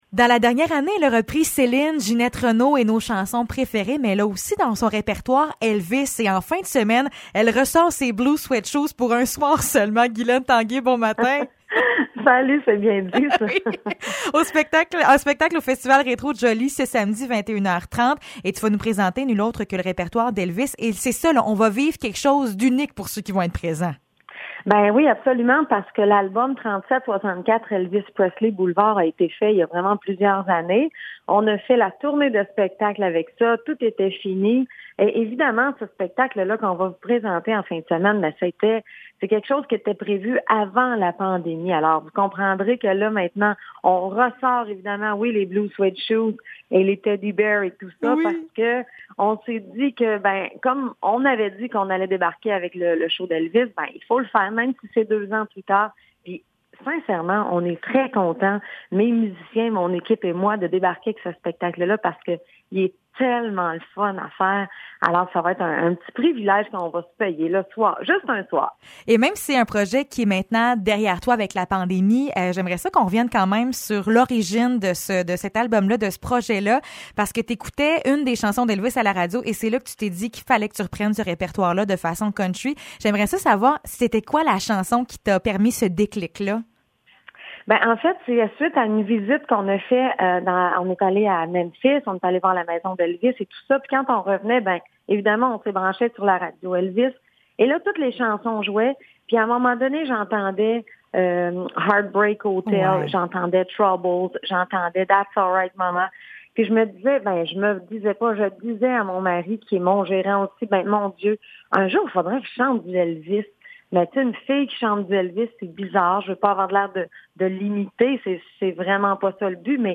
Entrevue avec la chanteuse Guylaine Tanguay (29 juillet 2022)
ENTREVUE-GUYLAINE-TANGUAY-28-JUILLET-ELVIS-SHOW.mp3